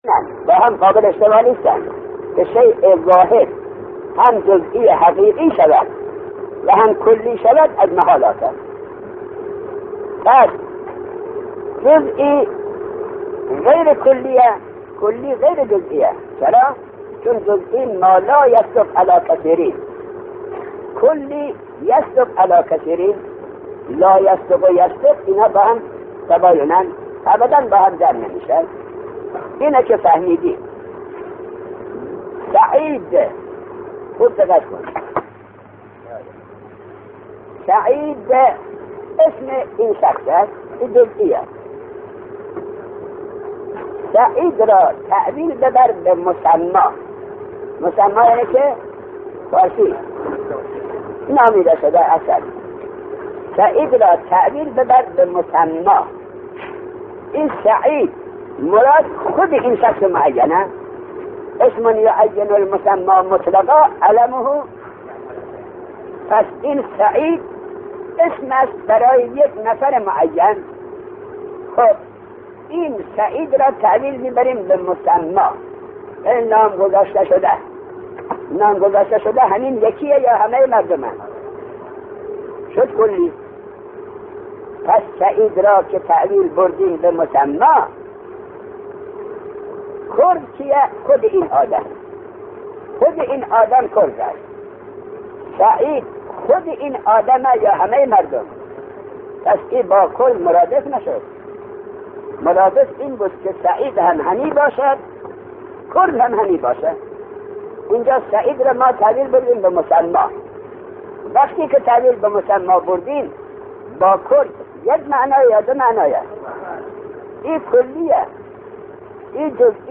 دانلود فایل صوتی تدریس کتاب صمدیه - مدرس افغانی